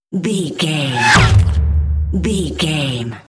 Sci fi whoosh to hit
Sound Effects
Atonal
No
dark
futuristic
intense
tension